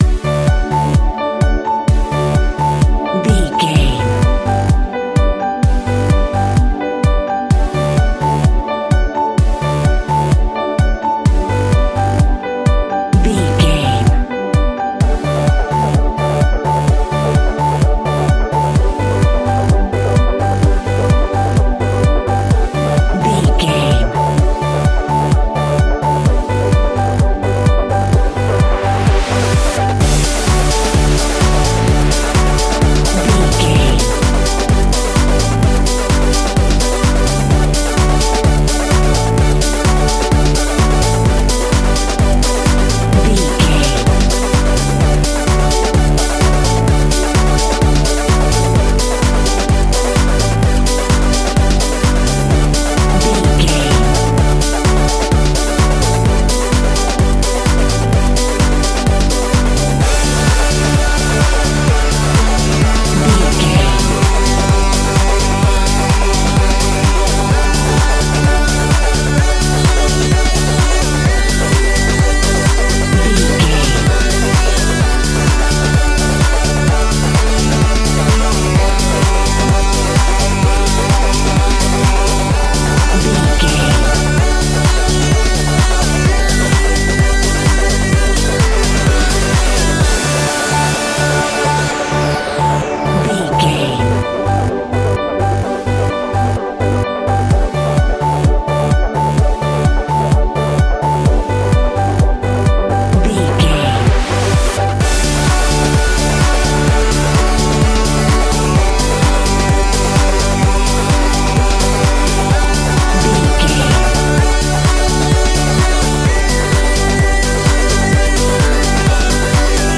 Ionian/Major
D
groovy
uplifting
futuristic
energetic
bouncy
synthesiser
drum machine
electronica
synthwave
synth leads
synth bass